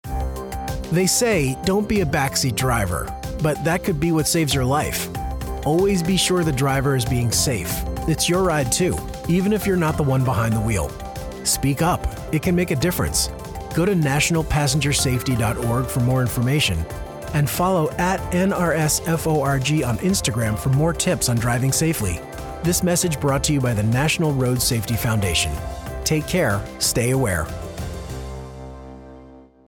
This collection of Public Service Announcements (PSAs) emphasizes the importance of traffic safety for all road users.